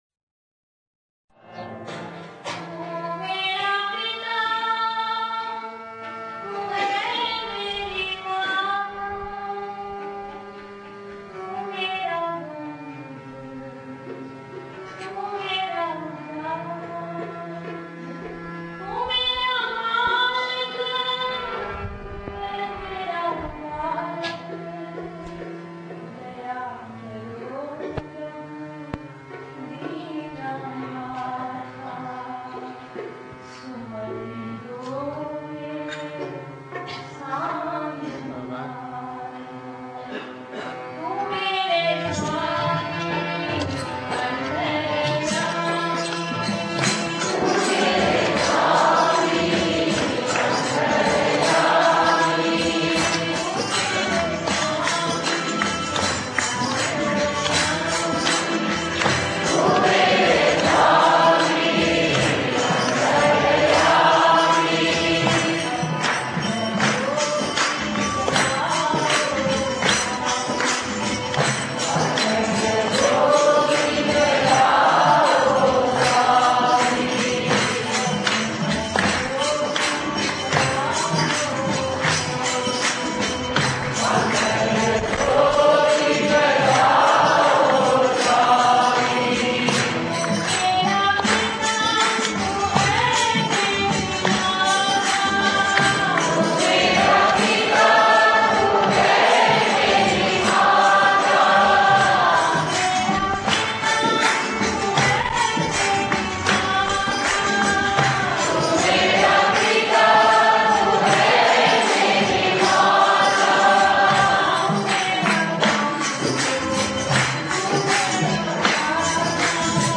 1. Devotional Songs
Natabhairavi
8 Beat / Keherwa / Adi
Medium Slow
6 Pancham / A